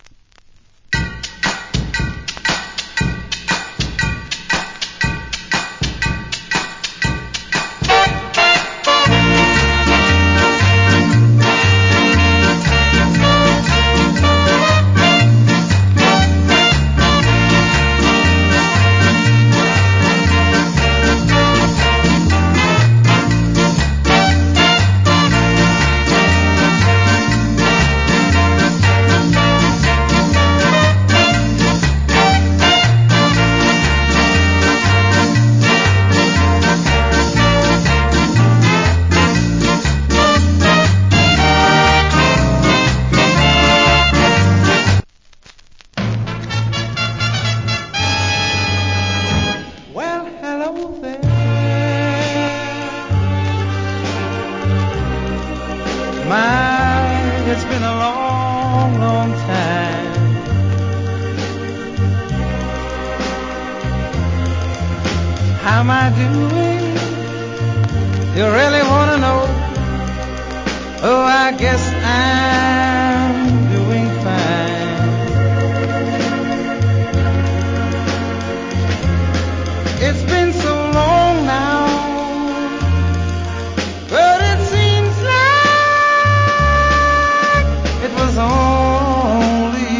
Good Ska Inst.